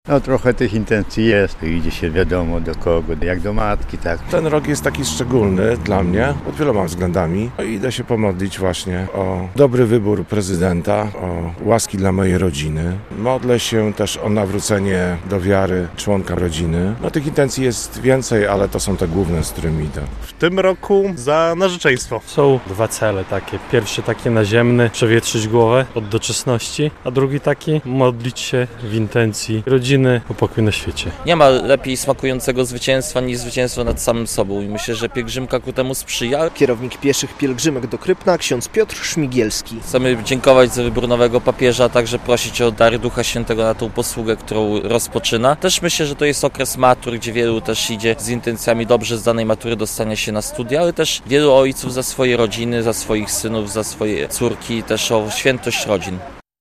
Jubileuszowa pielgrzymka mężczyzn do Krypna - relacja